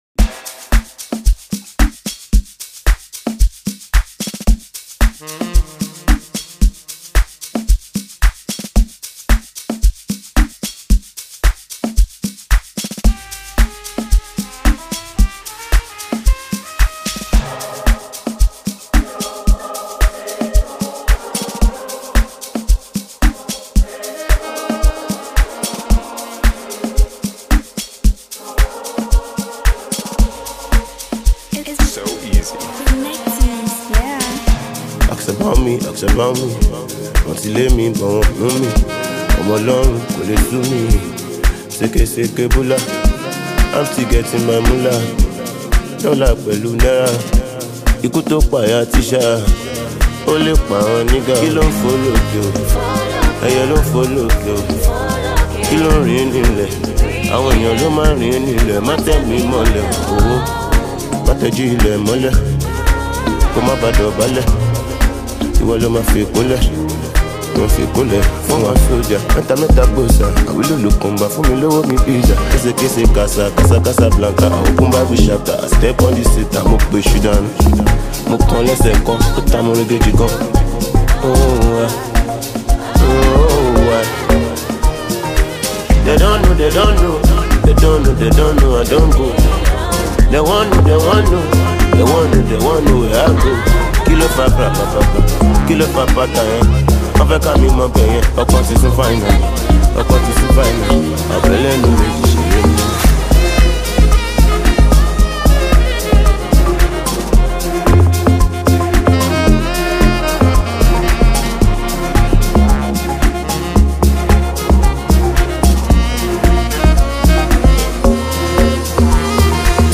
Well renowned Nigerian artist and performer
thrilling new gbedu song